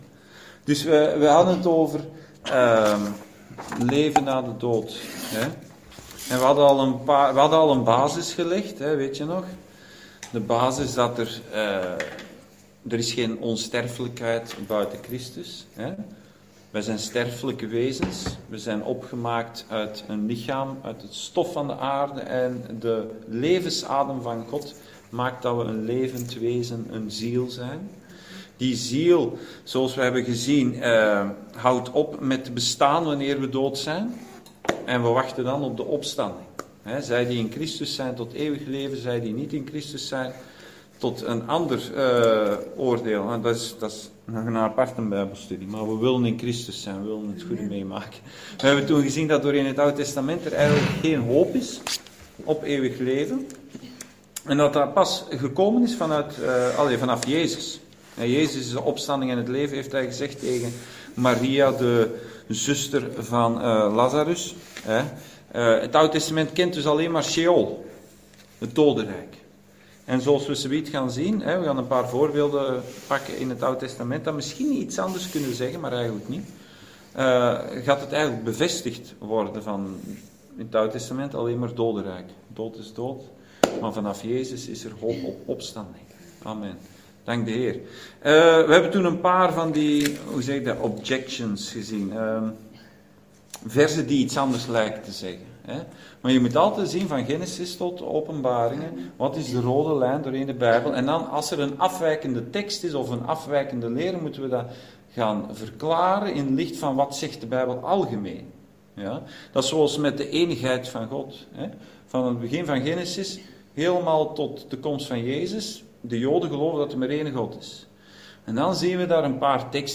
Bijbelstudie: leven na de dood: deel 2